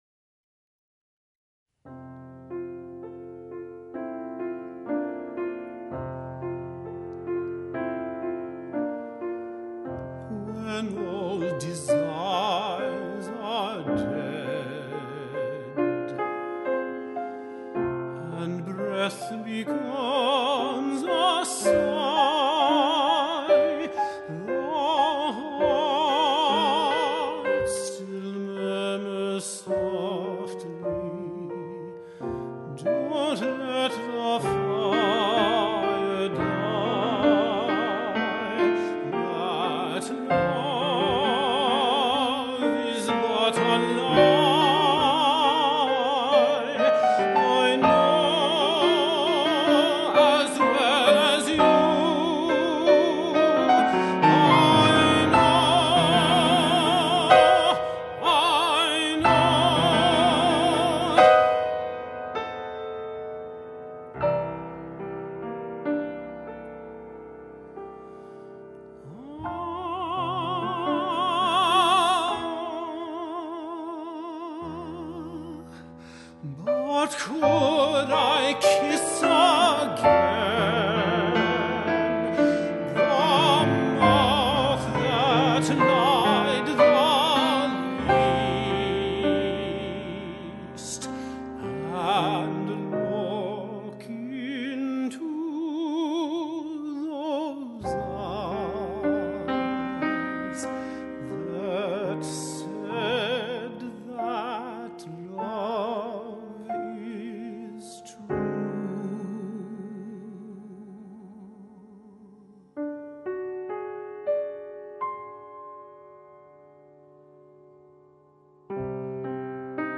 Vocal Language Collections, American-English